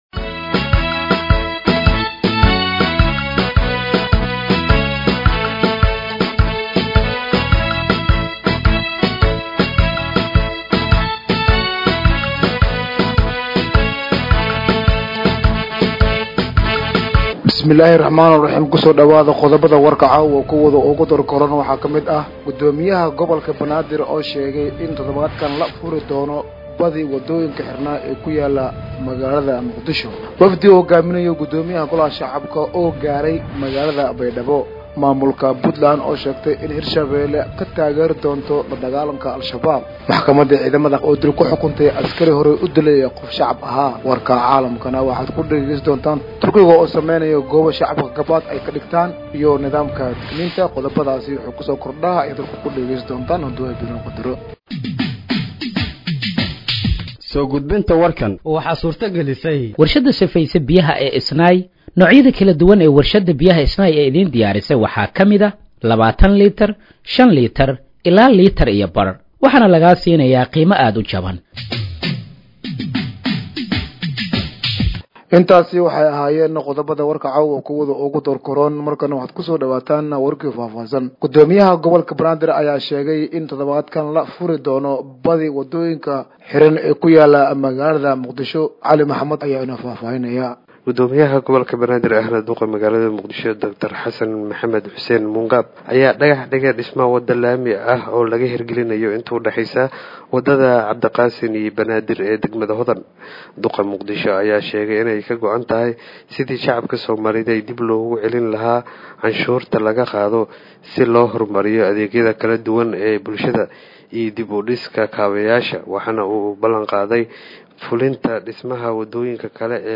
Dhageeyso Warka Habeenimo ee Radiojowhar 02/08/2025